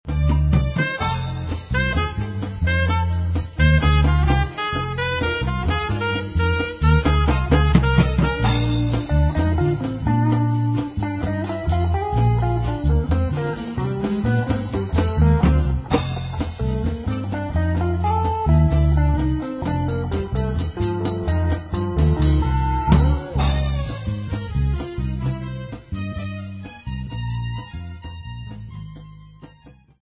guitarist
jazz